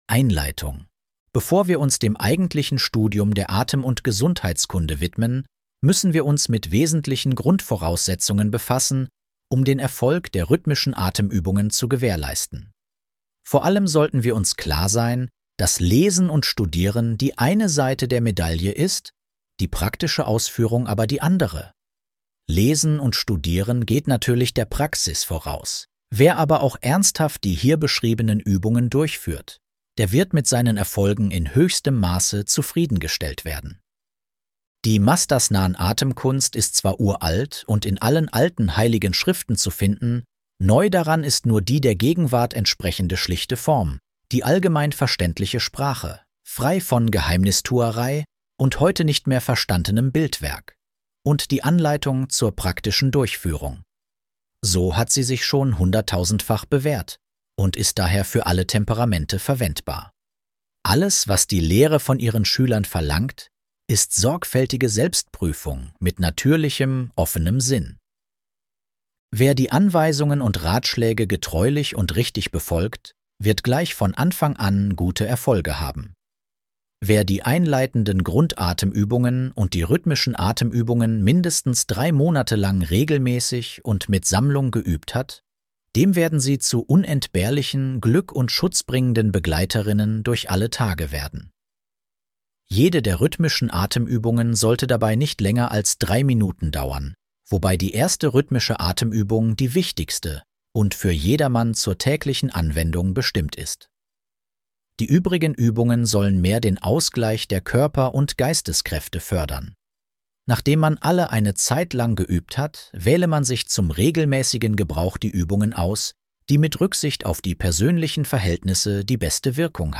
Atemkunde Hörbuch